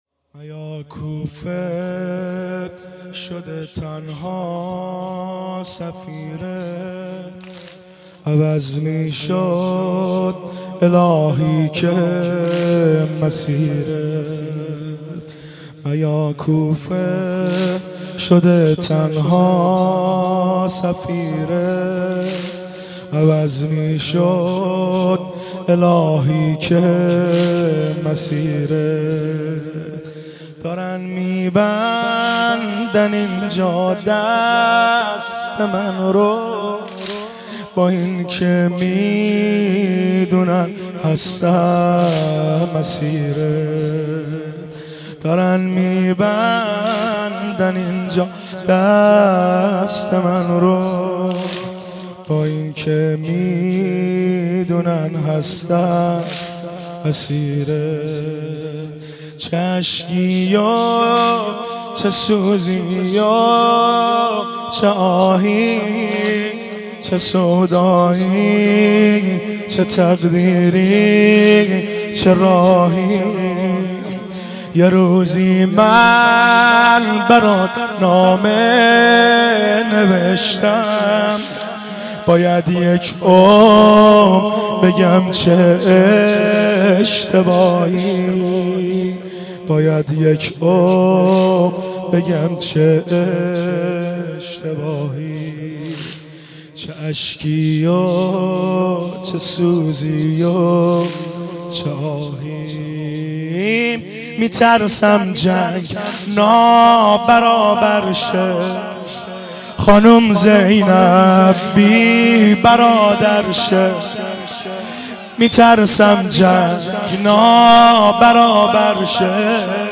صوت مراسم شب اول محرم ۱۴۳۷ هیئت غریب مدینه امیرکلا ذیلاً می‌آید: